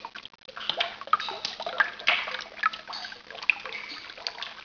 drips02.wav